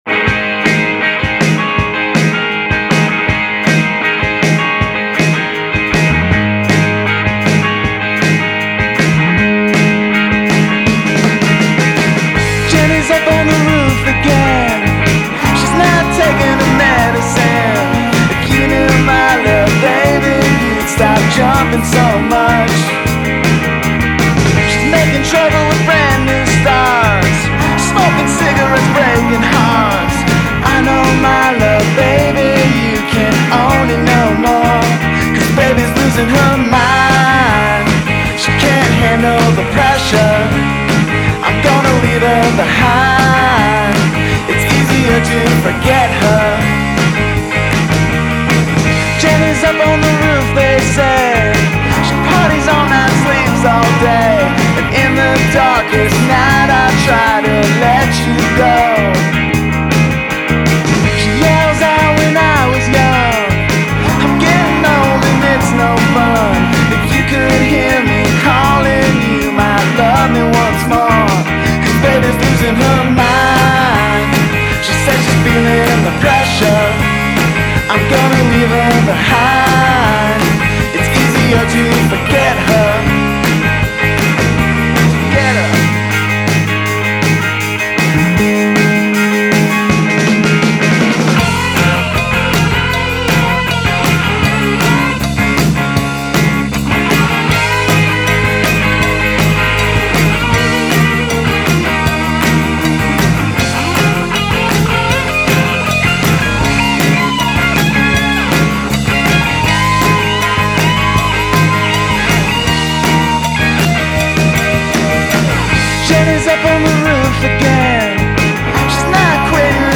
fun and whimsical